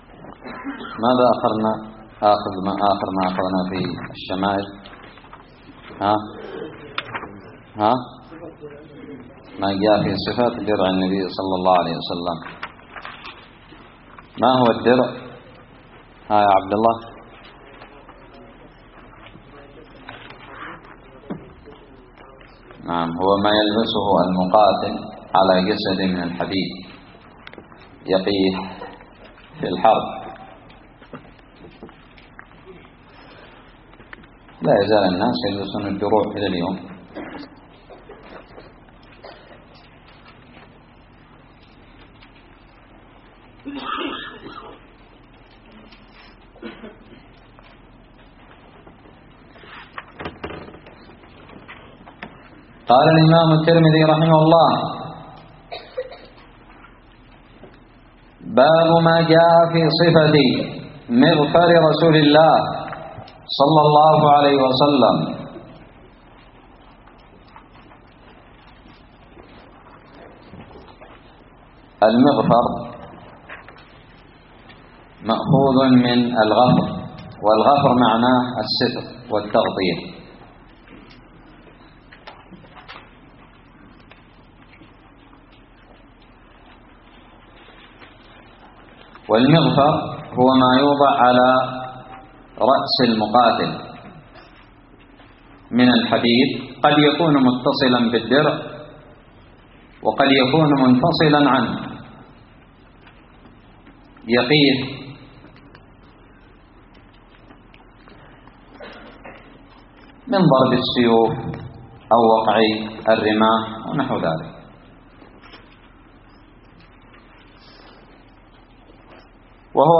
الدرس السابع والثلاثون من شرح كتاب الشمائل المحمدية
ألقيت بدار الحديث السلفية للعلوم الشرعية بالضالع